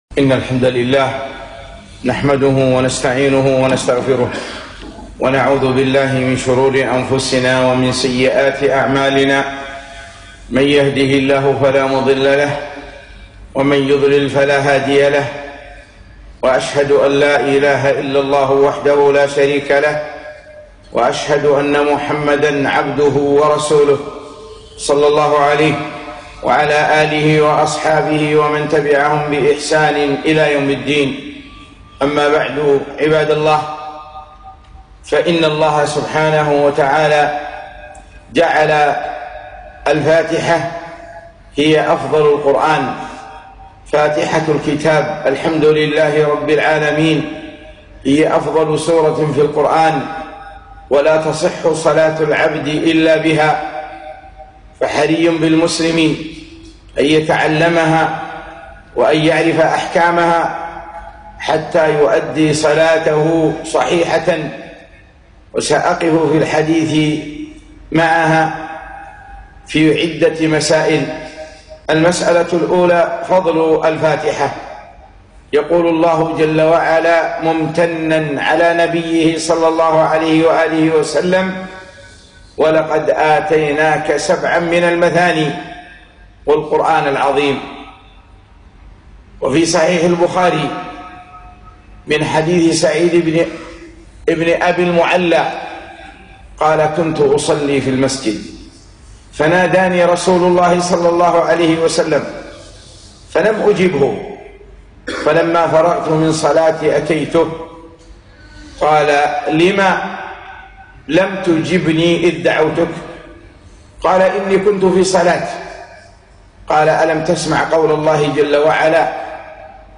خطبة - مسائل مهمة في سورة الفاتحة